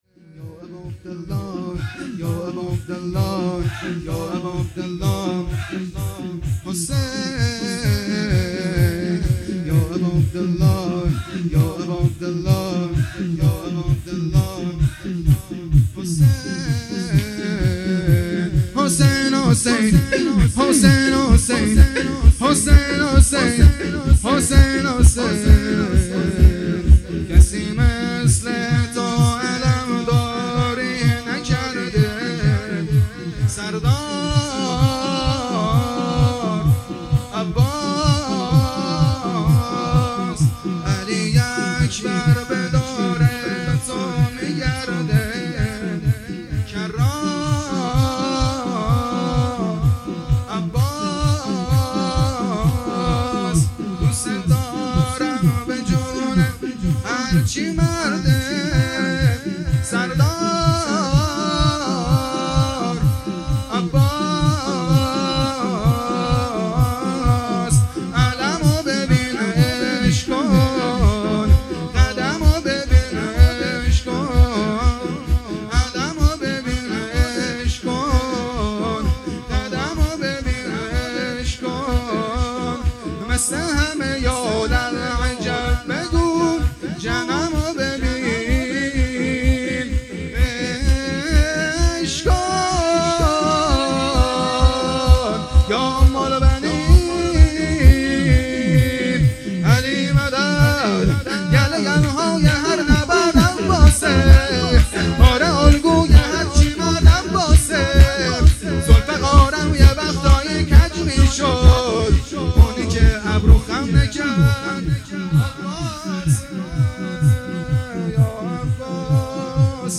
شب اول - دهه اول محرم 1404